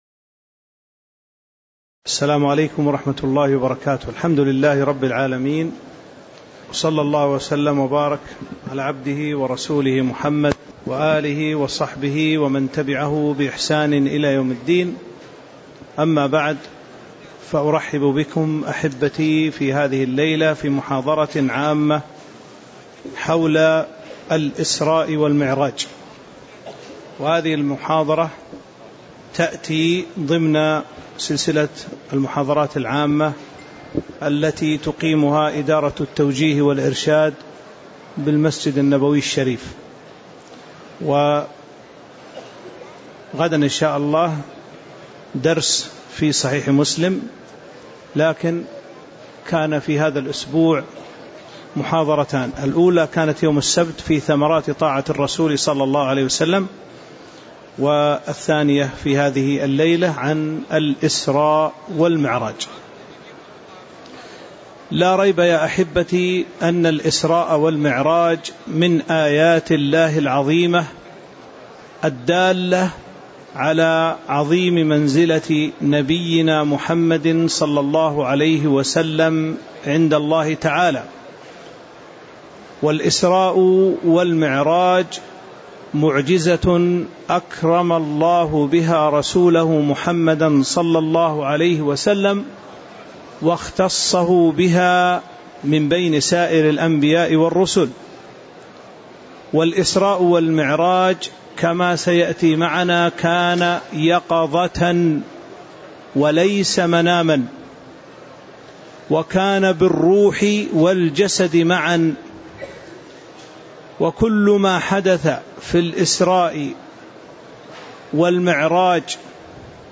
تاريخ النشر ٢٢ رجب ١٤٤٤ هـ المكان: المسجد النبوي الشيخ